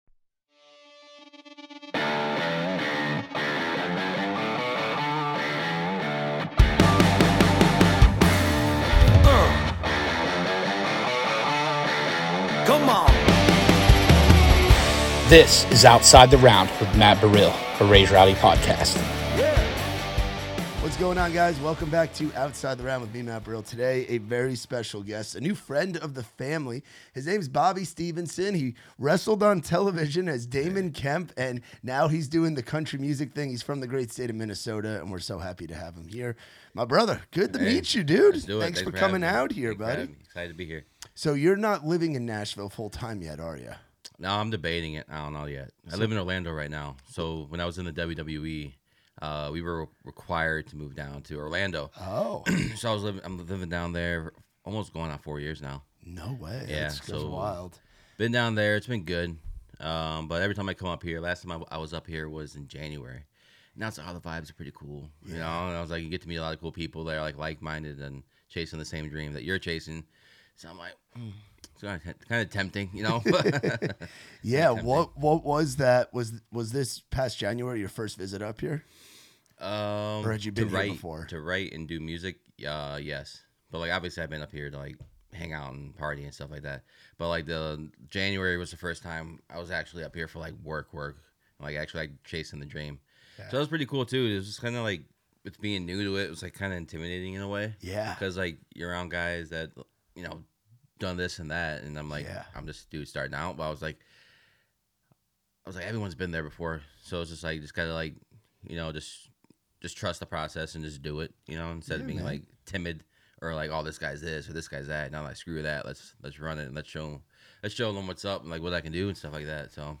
From green room hangs to late-night dives, each episode dives into the stories, struggles, and wild moments behind the music. Part of the Raised Rowdy Podcast Network.
Interviews